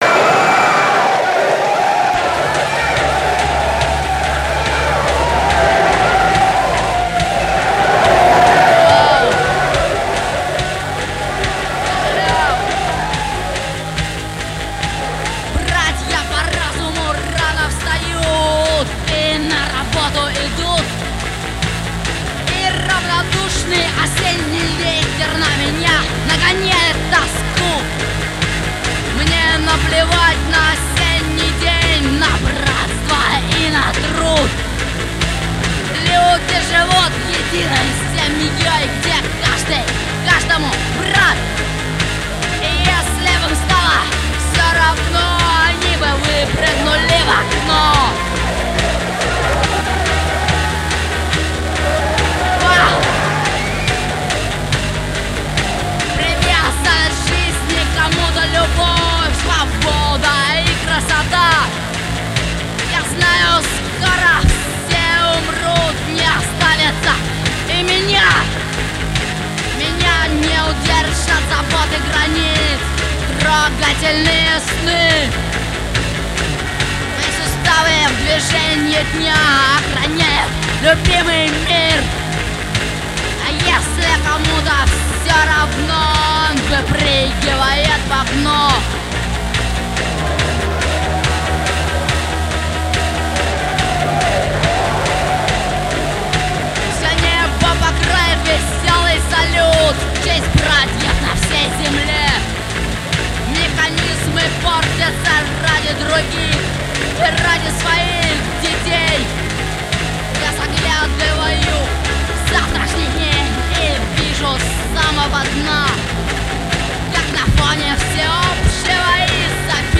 жужжащей гитарой с булькающим ритм-боксом.
(вокал)
(гитара)
(ритм-бокс)